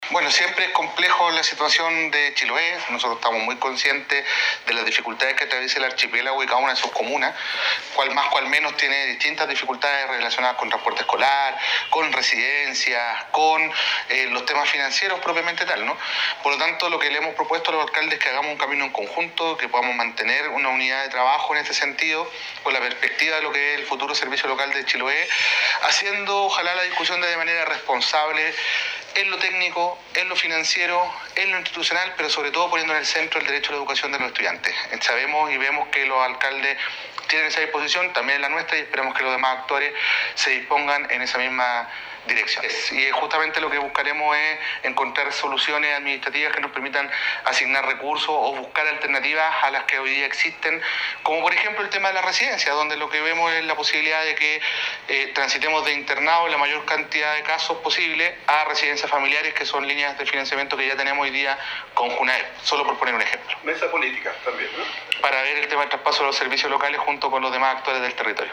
En lo que respecta al encuentro con el ministro Nicolás Cataldo, se espera avanzar hacia una mesa de trabajo permanente para tratar temáticas tan relevantes como la instalación de los SLEP, servicio local de educación pública, entre otros compromisos, señaló el titular de la cartera.